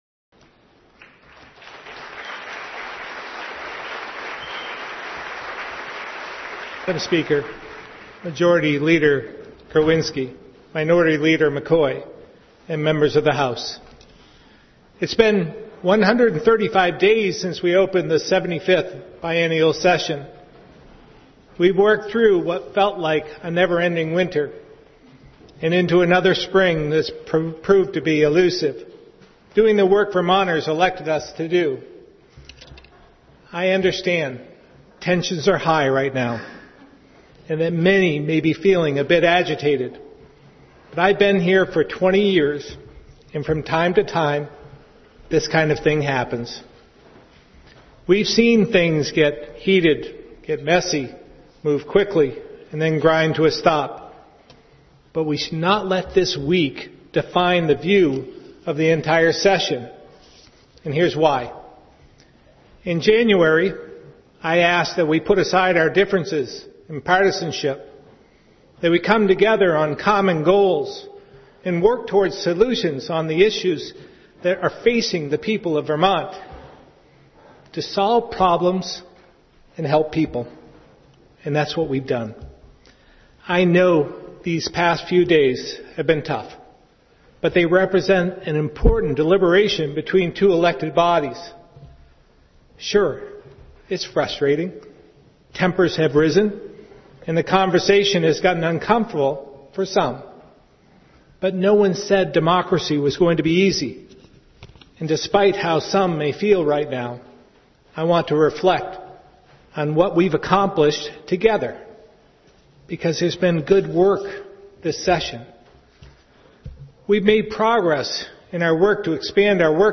Listen to Gov. Phil Scott’s closing speech: